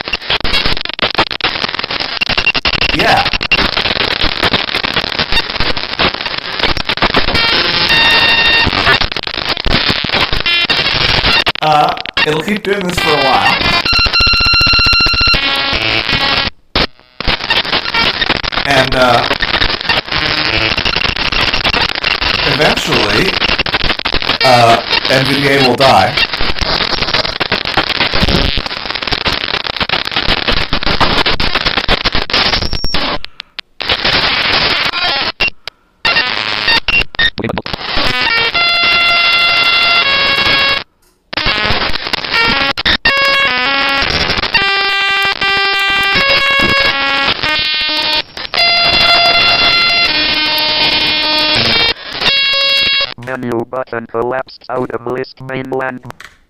Warning: loud noises.
Unfortunately, if I arrow up and down quickly on a webpage, this is what it frequently does. Sorry for the echo and horrible clipping, I had to shout over the noises, and the setup to record this wasn't ideal. But I haven't heard a computer make these noises in ages!